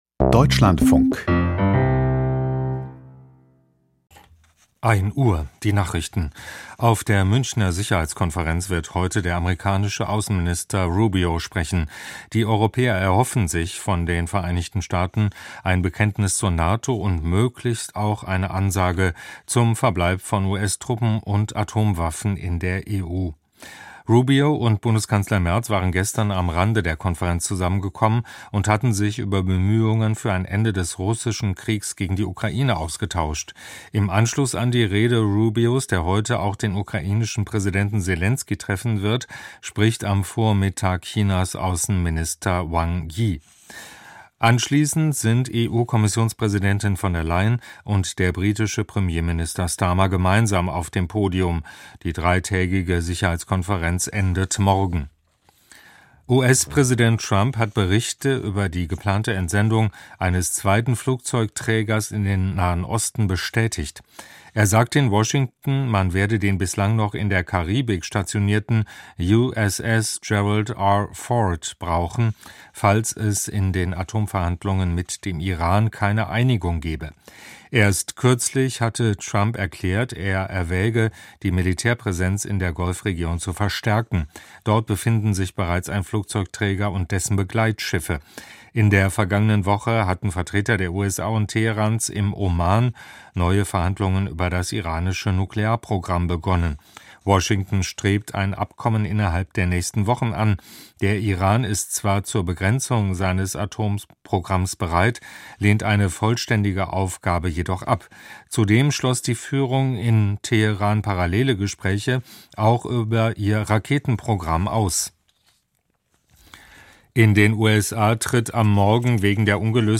Aus der Deutschlandfunk-Nachrichtenredaktion.